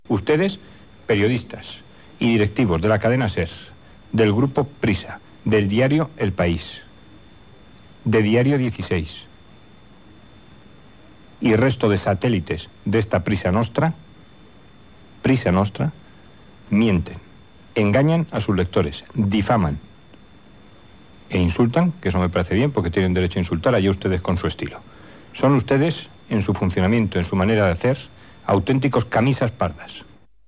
Crítica al Grupo Prisa Gènere radiofònic Info-entreteniment Presentador/a Herrero, Antonio